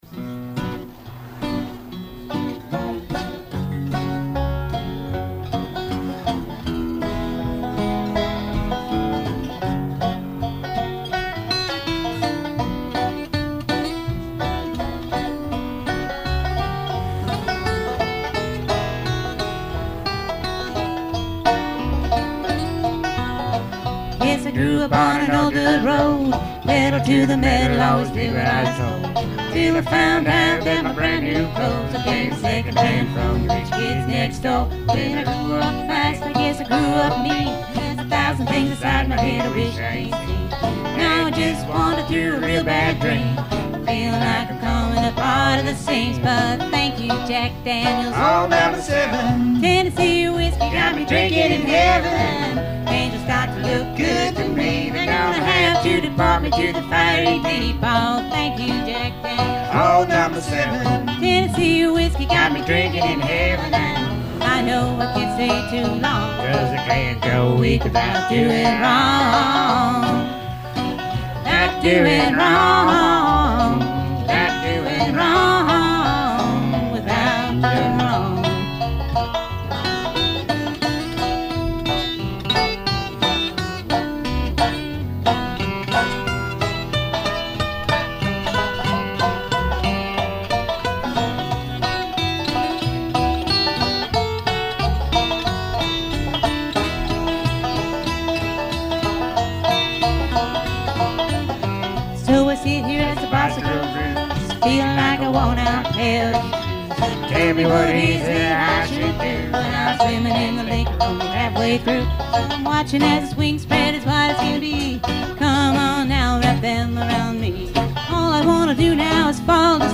recorded live